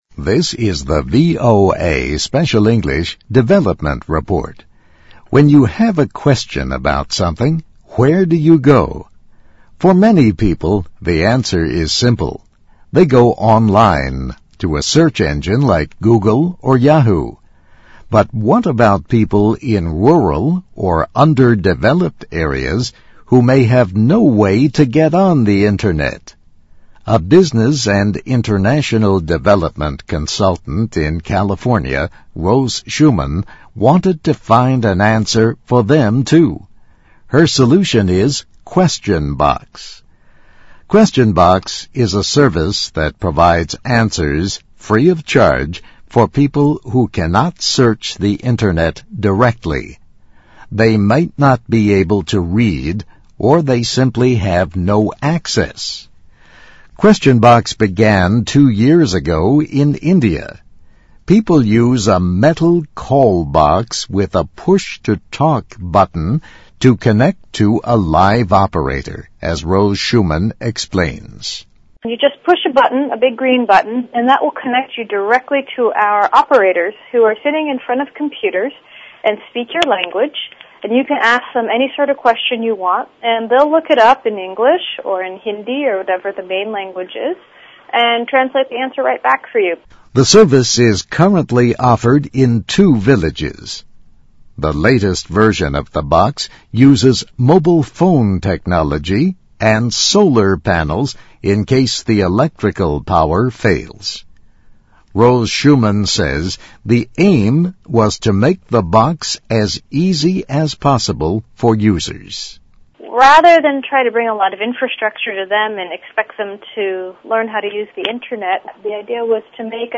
VOA慢速英语2009年-Development Report - Need an Answer? Just 听力文件下载—在线英语听力室